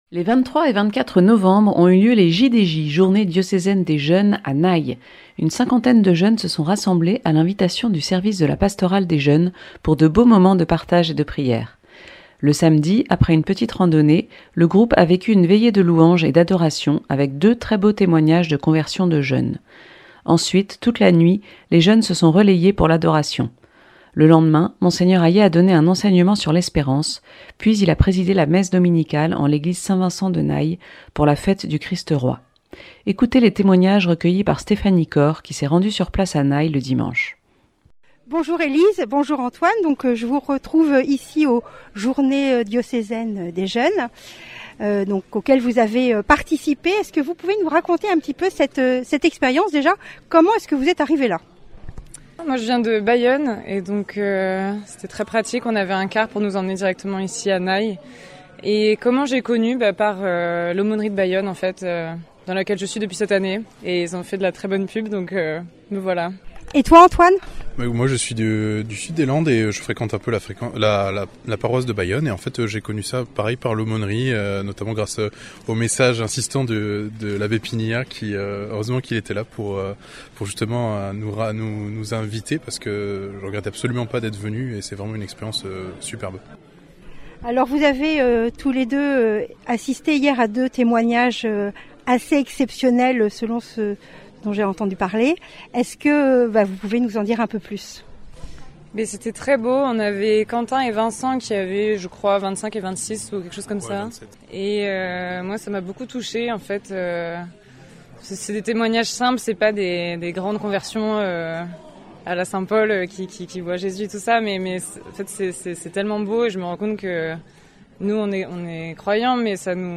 Témoignages de jeunes interviewés